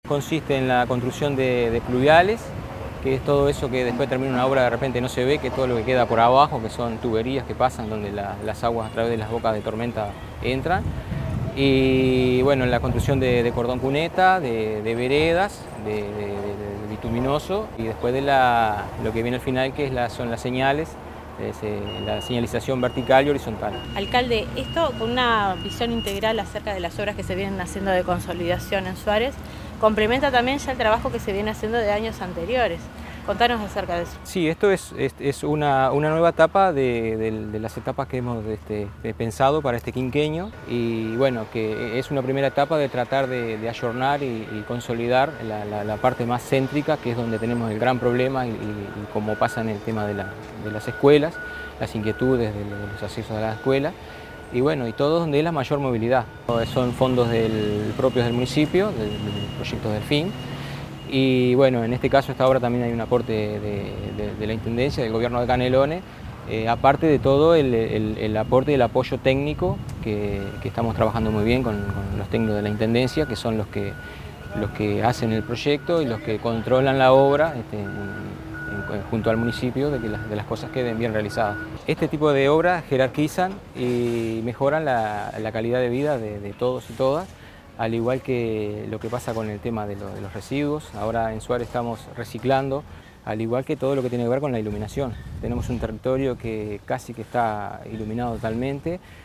carlos_nalerio_-_alcalde_de_suarez.mp3